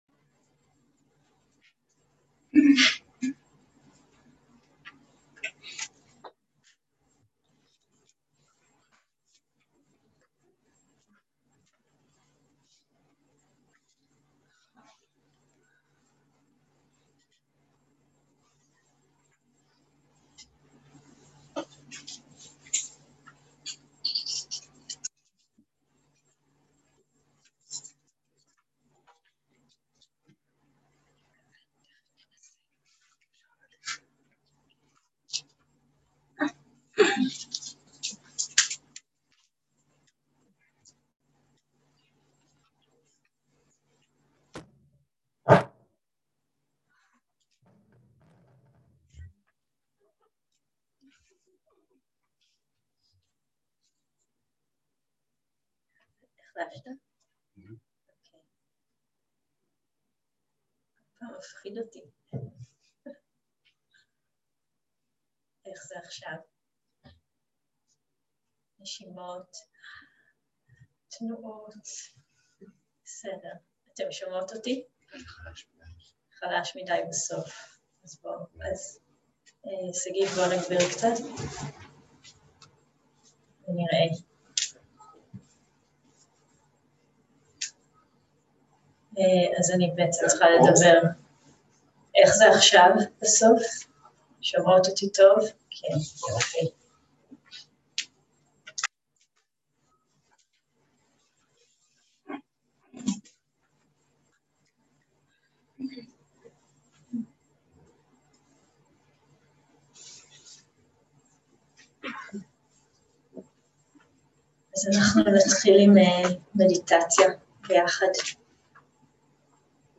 שיחת דהרמה - מטא, ריקות ותובנה בעולם
סוג ההקלטה: שיחות דהרמה